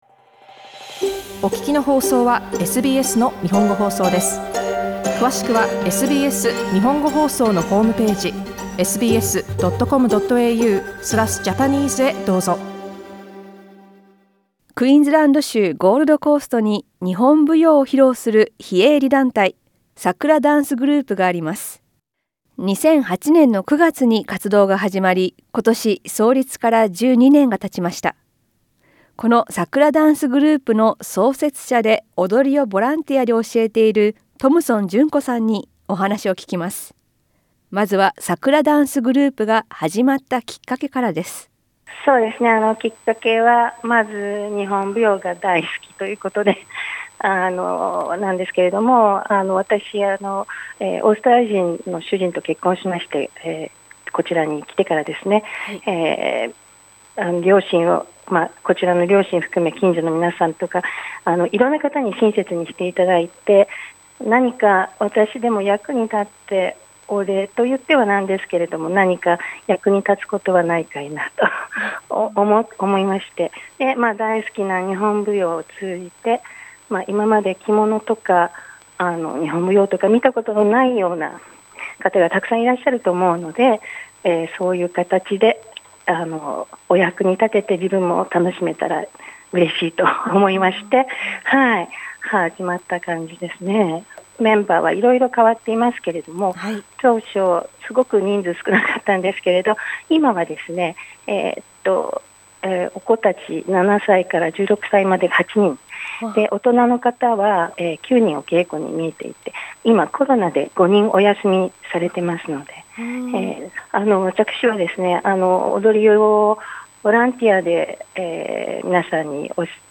インタビューでは、桜ダンスグループの活動についてや、コロナウイルスの影響などを聞きました。